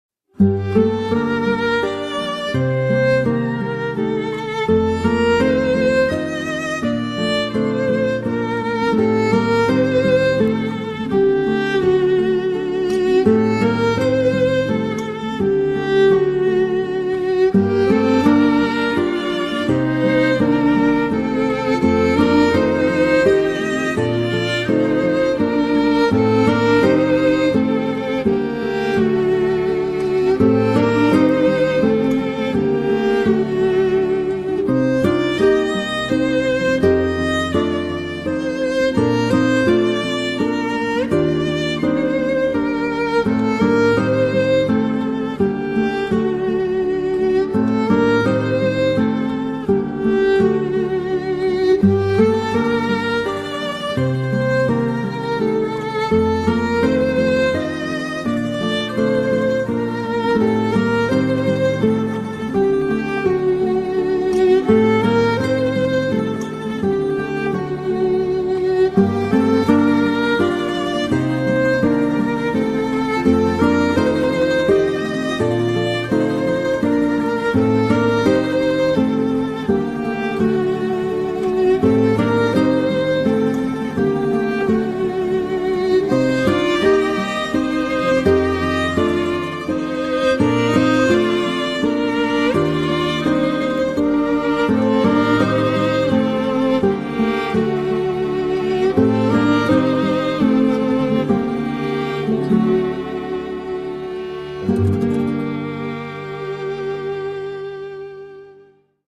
tema dizi müziği, mutlu huzurlu rahatlatıcı fon müzik.